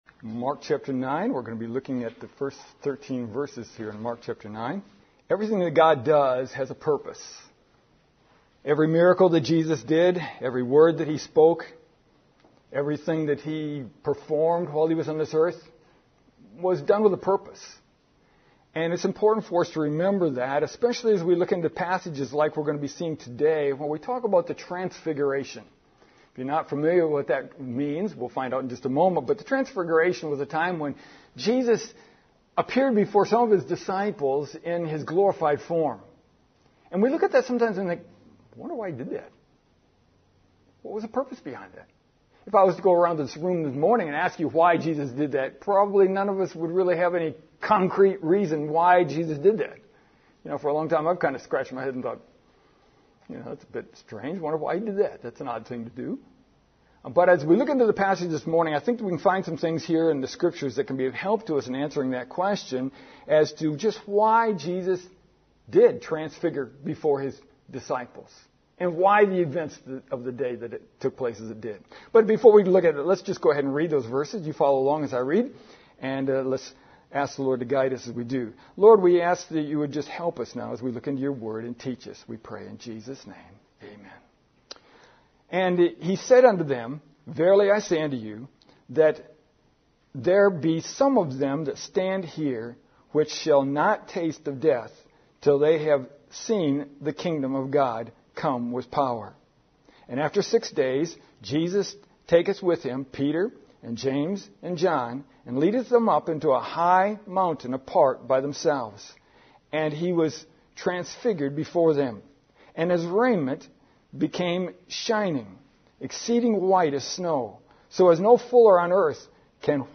Why did Jesus transfigure before the disciples? This morning I want us to note three reasons for the transfiguration of Christ.